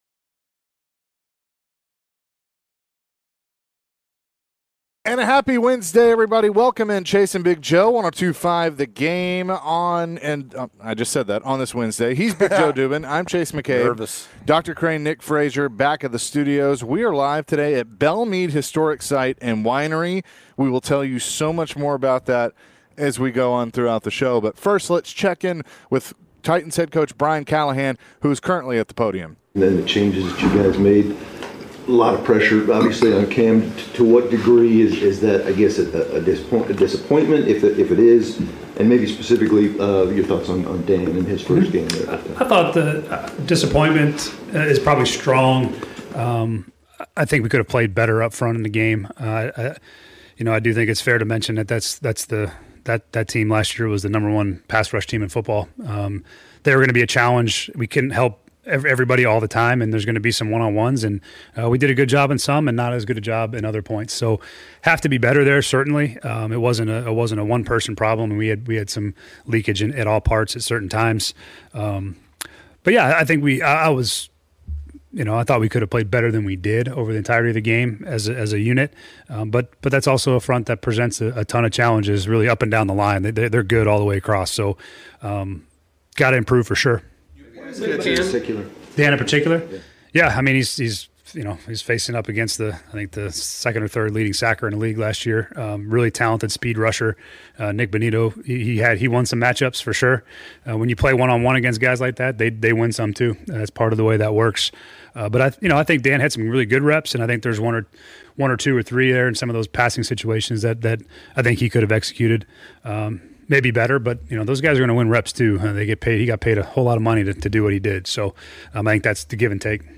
Later in the hour, MTSU Head Coach Derek Mason joined the show ahead of his team's week three matchup.